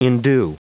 Prononciation du mot endue en anglais (fichier audio)
Prononciation du mot : endue